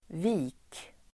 Uttal: [vi:k]